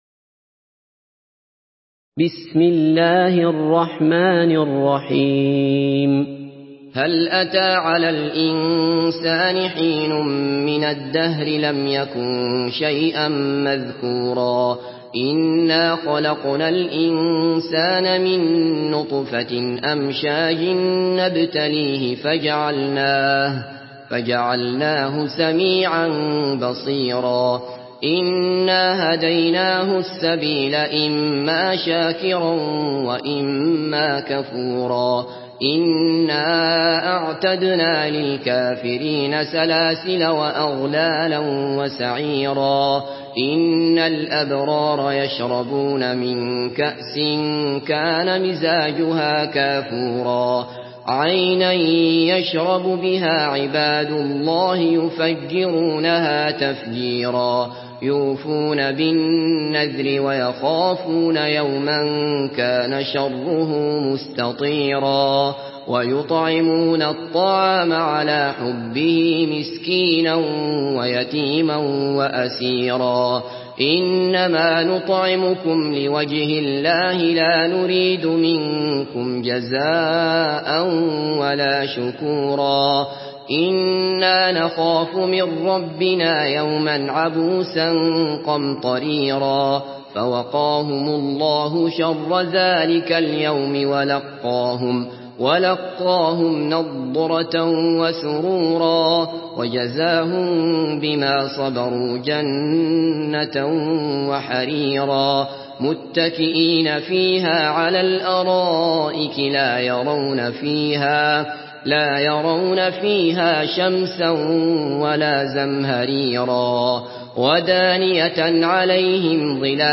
Surah Insan MP3 by Abdullah Basfar in Hafs An Asim narration.
Murattal Hafs An Asim